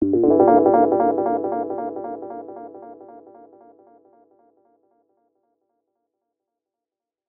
Echoes_G_02.wav